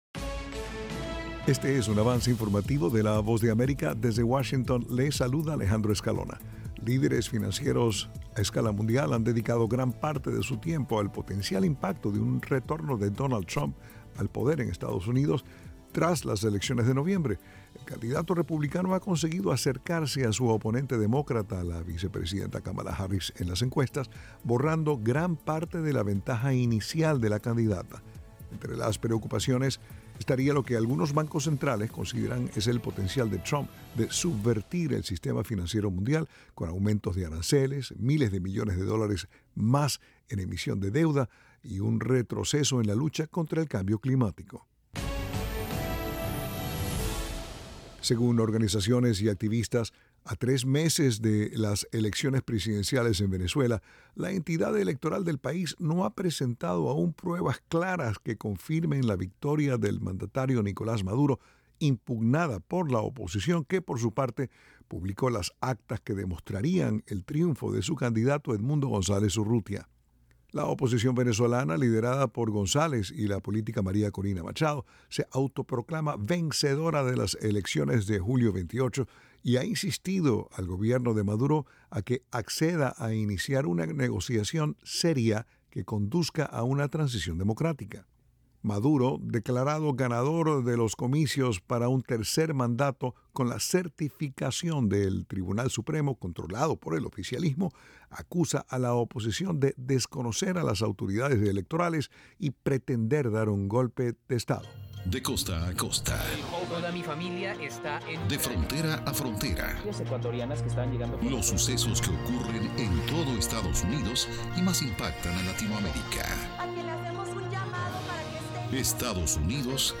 Avance Informativo
El siguiente es un avance informativo presentado por la Voz de América en Washington.